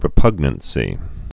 (rĭ-pŭgnən-sē)